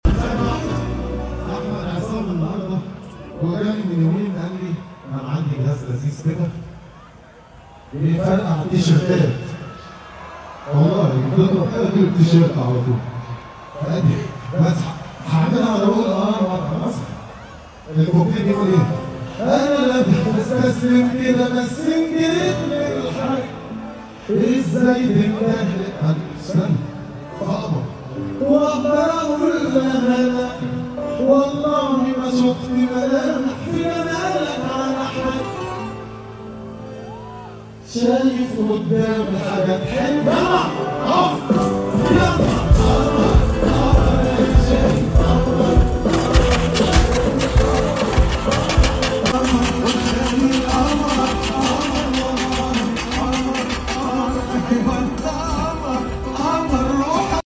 Al Alamein Festival 2025